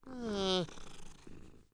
Npc Catpurr Sound Effect
npc-catpurr-3.mp3